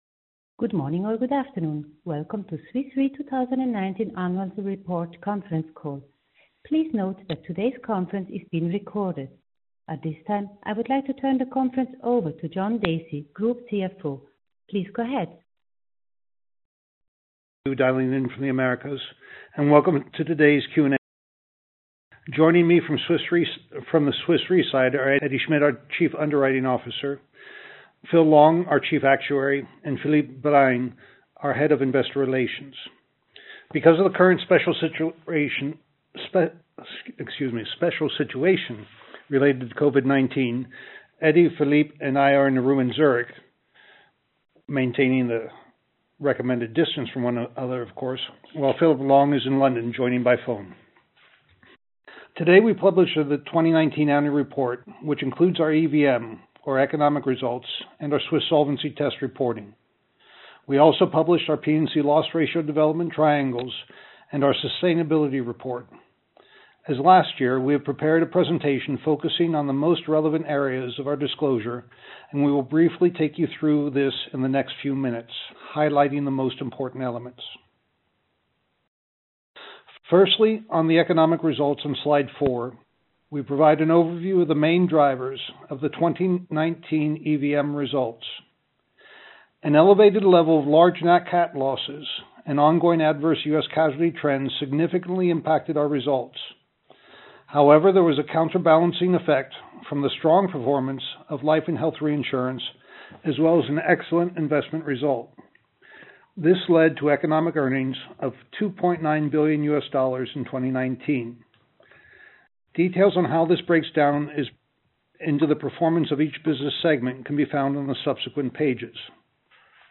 ar-2019-call-recording.mp3